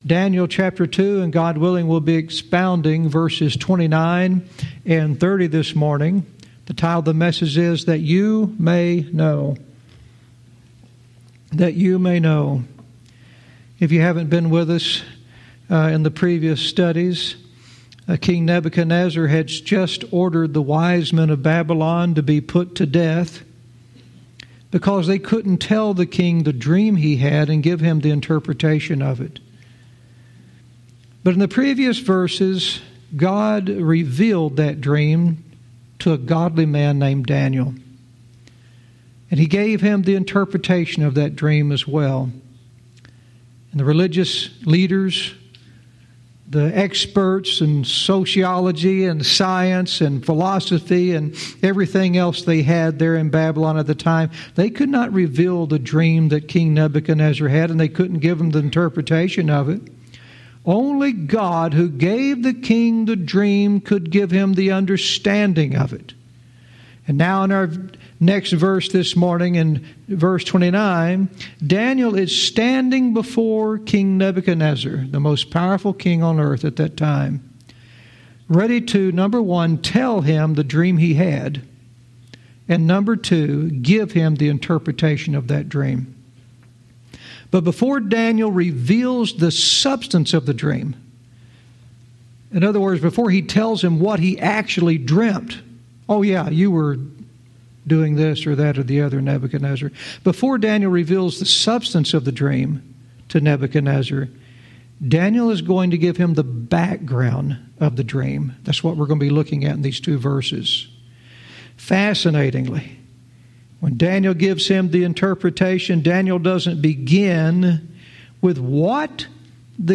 Verse by verse teaching - Daniel 2:29-30 "That You May Know"